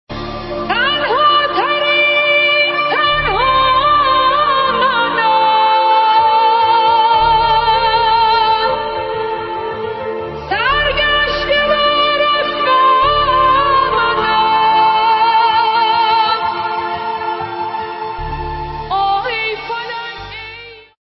موسیقی ریمیکس شده ی زیبای و جدید